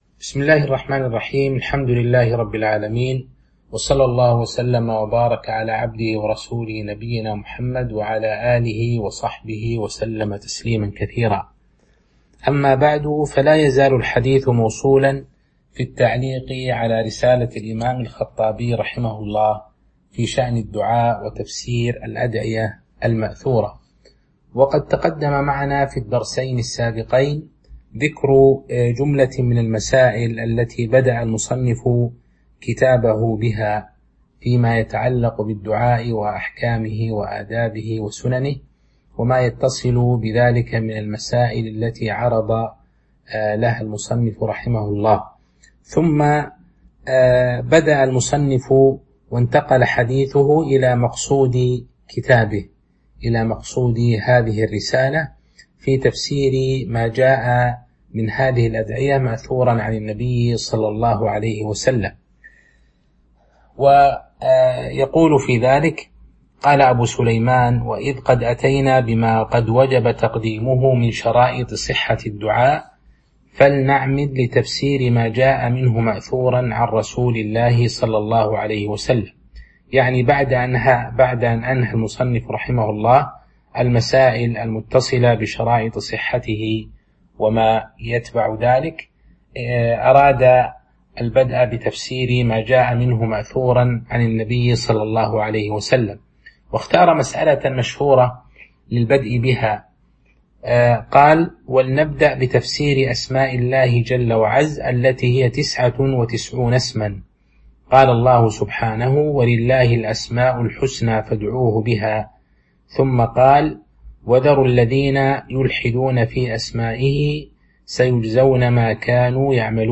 تاريخ النشر ٨ رمضان ١٤٤٢ هـ المكان: المسجد النبوي الشيخ